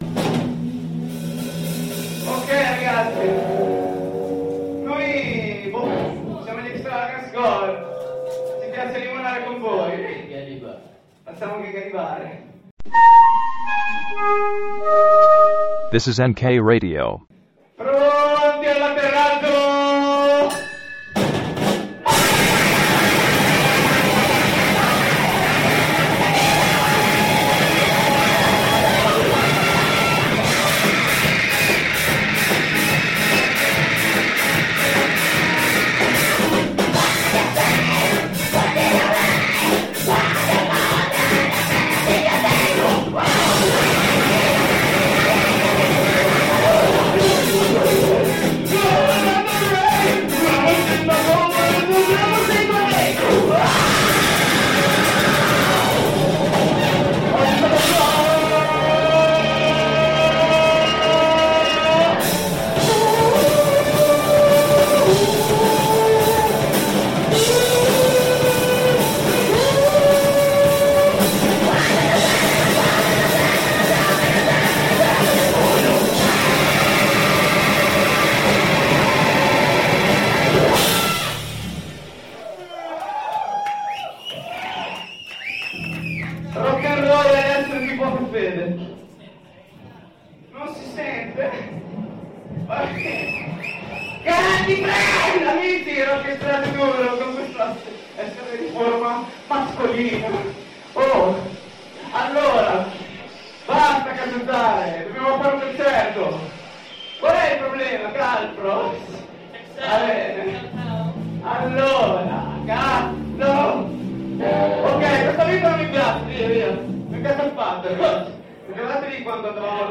The SINK #86 – Live al CSA Spartaco (1 di 3) | Radio NK